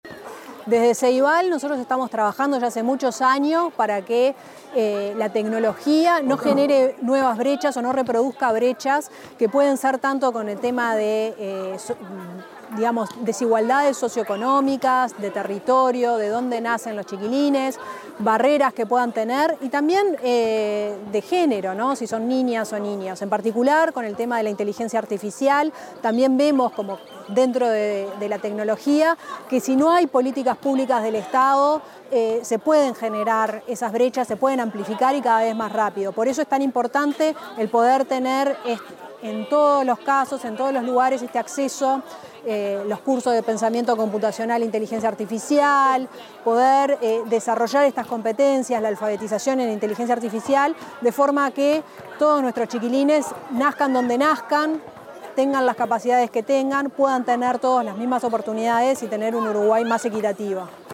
Declaraciones de la presidenta de Ceibal, Firella Haim
Declaraciones de la presidenta de Ceibal, Firella Haim 18/10/2025 Compartir Facebook X Copiar enlace WhatsApp LinkedIn La presidenta de Ceibal, Firella Haim, expuso en la celebración de los 15 años del Movimiento Minga, en el marco del Día Internacional para la Erradicación de la Pobreza.